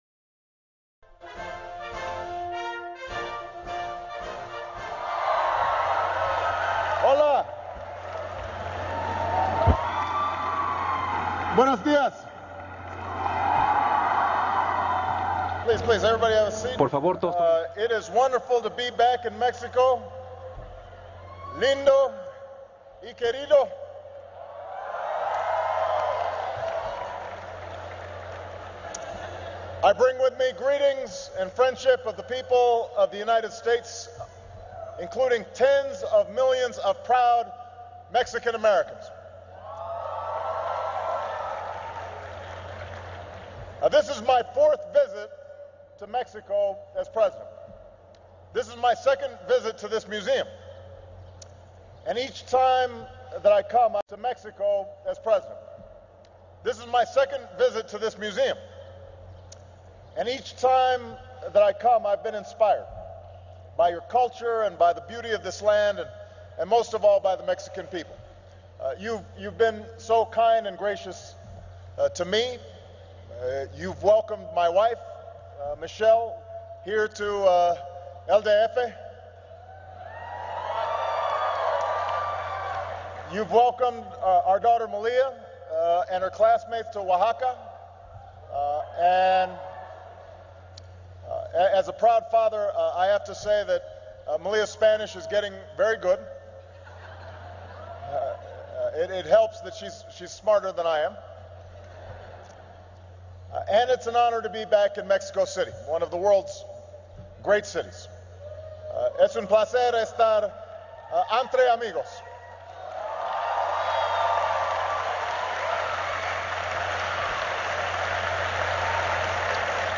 U.S. President Barack Obama speaks to Mexican students at the Anthropology Museum in Mexico City
President Obama speaks to Mexican students at the Anthropology Museum in Mexico City. Obama talks about the status of relations between the U.S. and Mexico. He addresses several topics including immigration, cross-border drug and weapon trafficking, and economic cooperation. Obama concedes that demand for drugs in the United States has been at the root of much of the violence in Mexico.
Broadcast on C-SPAN, May 3, 2013.